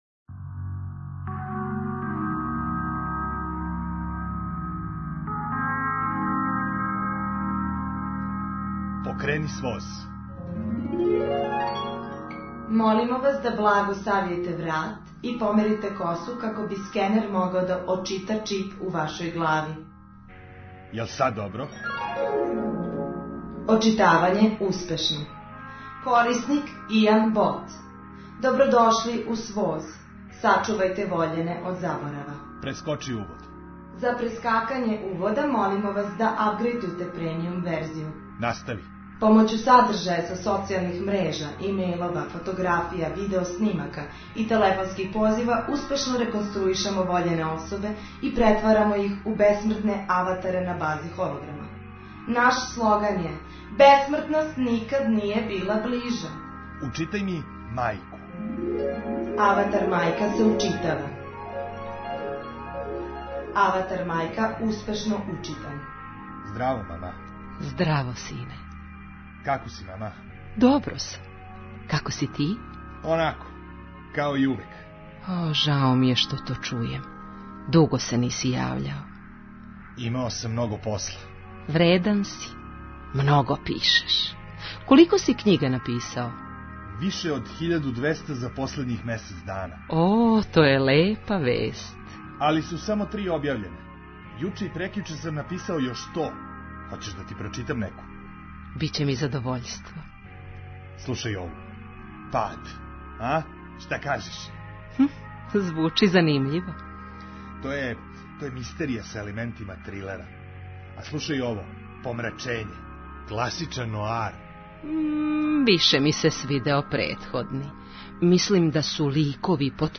Драмске минијатуре